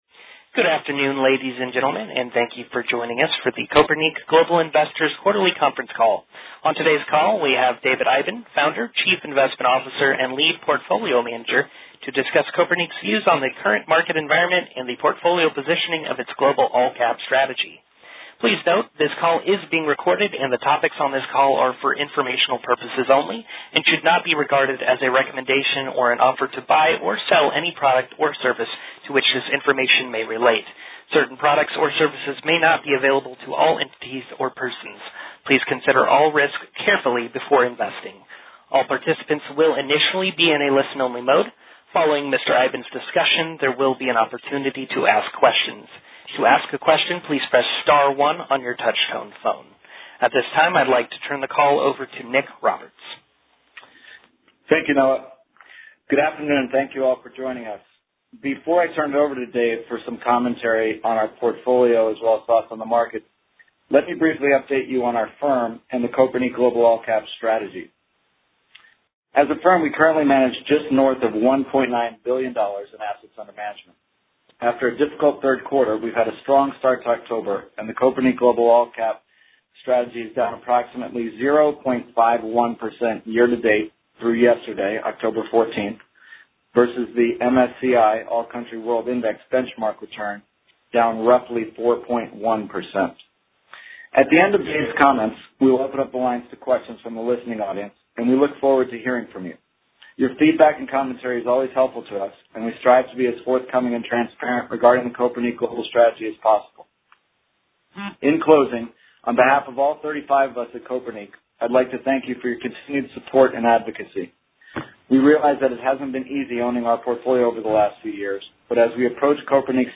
Q3 2015 Conference Call - Kopernik Global Investors